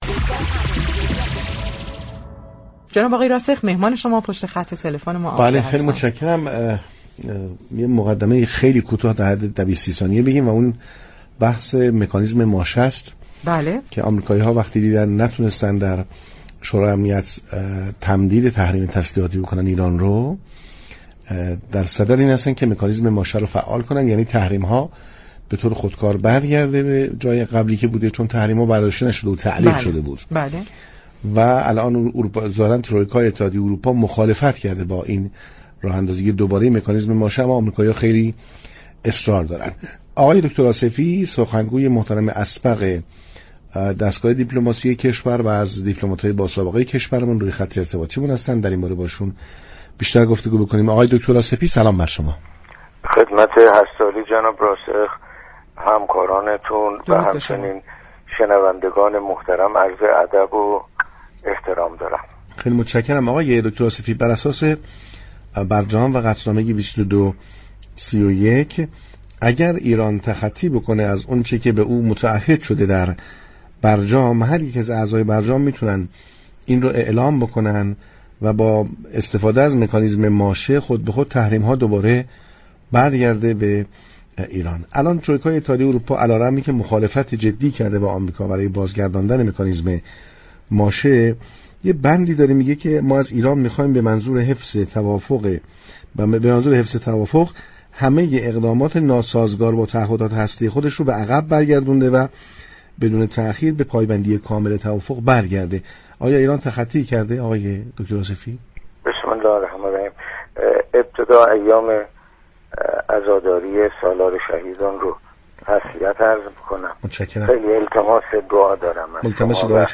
دكتر حمیدرضا آصفی سخنگوی اسبق وزارت امورخارجه در گفتگو با پارك شهر اظهار داشت: مكانیسم ماشه فعال نشده و فعال هم نخواهد شد و امریكا فقط به دنبال فضاییست تا بتواند مجددا با ایران مذاكره كند.